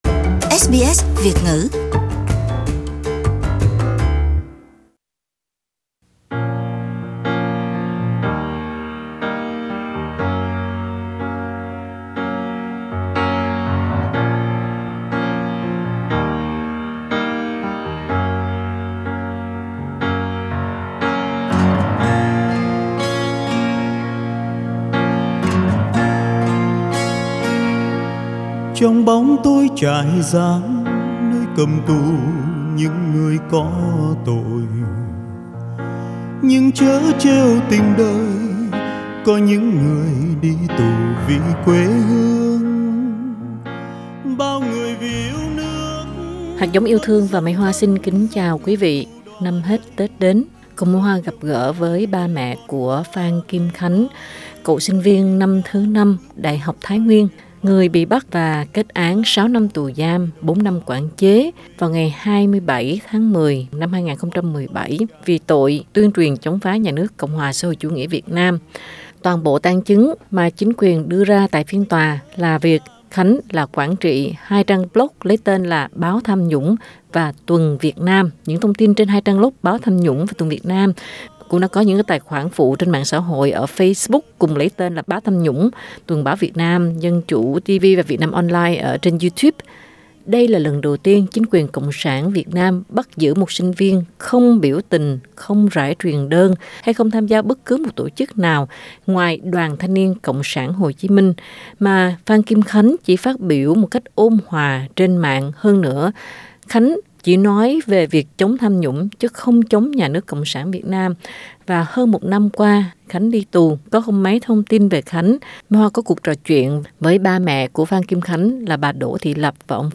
Trong cuộc trò chuyện